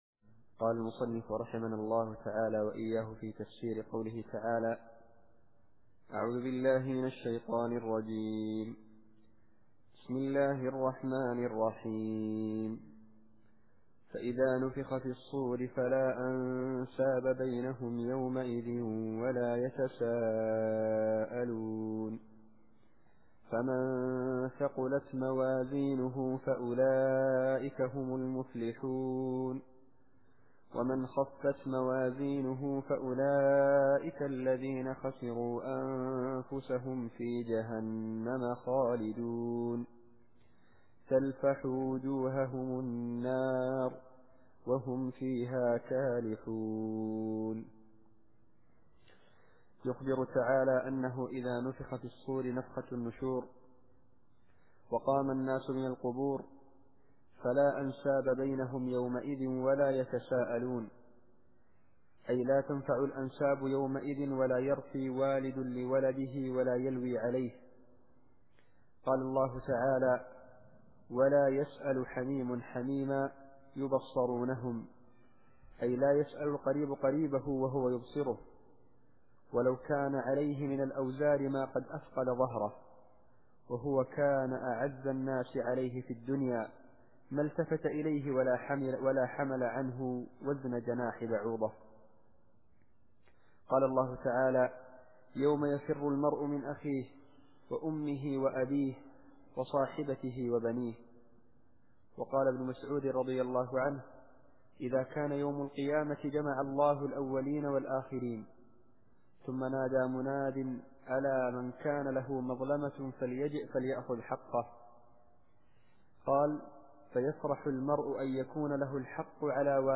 التفسير الصوتي [المؤمنون / 101]